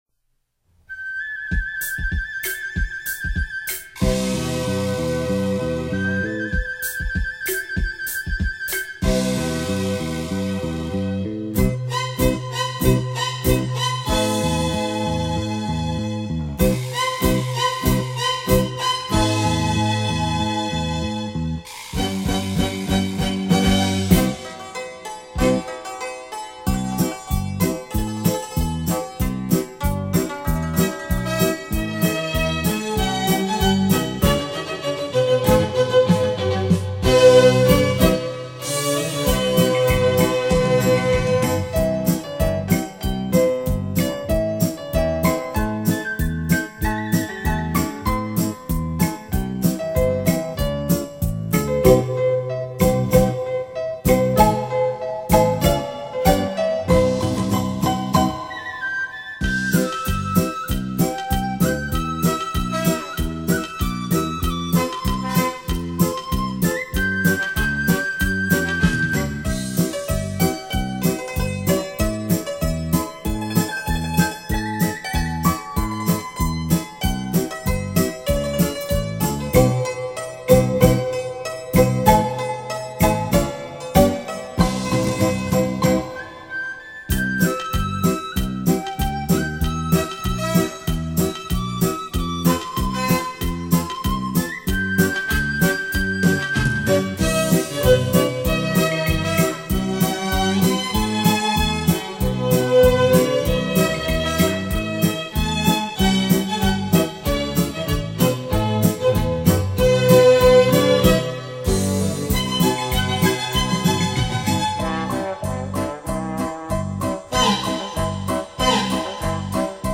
音乐类型： 轻音乐　　　　　　　　　.
吉鲁巴